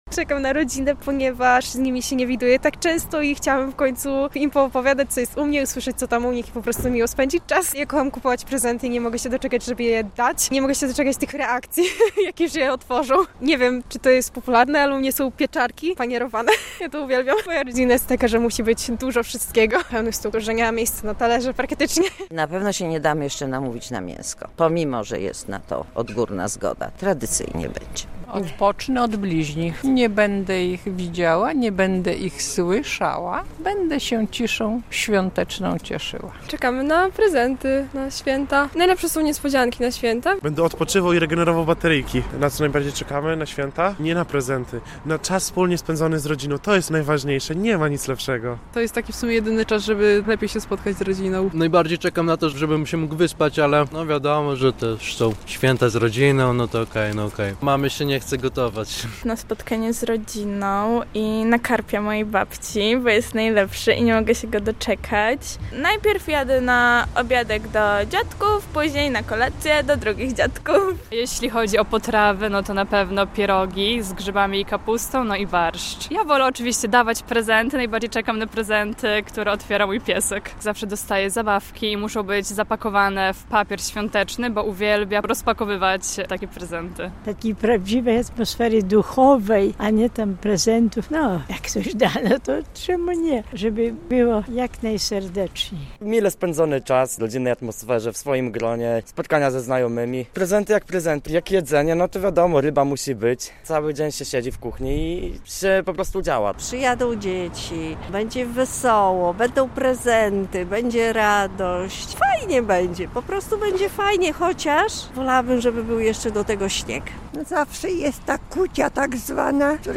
Mieszkańcy Białegostoku mówią, że najbardziej czekają na atmosferę świąt.